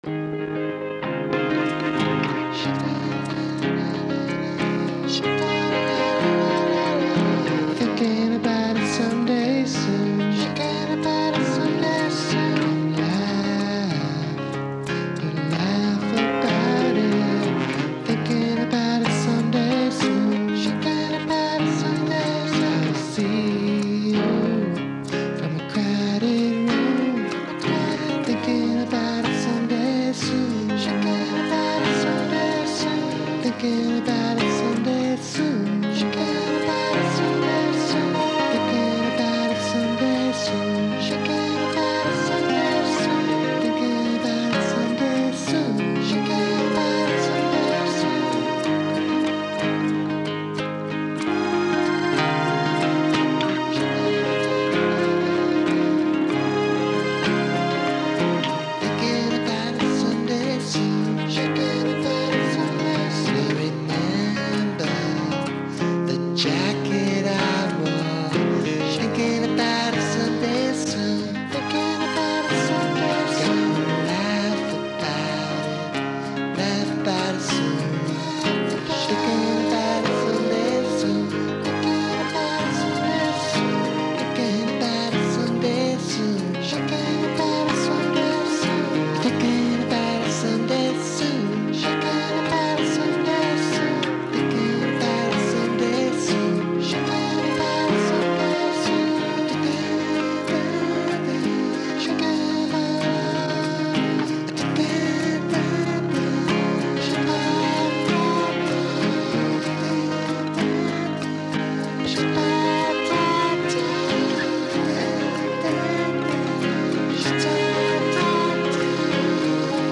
Tags: music folk pop original songs